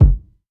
Tuned drums (G key) Free sound effects and audio clips
• Round Steel Kick Drum Sample G Key 624.wav
Royality free kickdrum tuned to the G note. Loudest frequency: 97Hz
round-steel-kick-drum-sample-g-key-624-OHb.wav